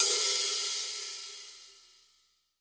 14HK OPEN.wav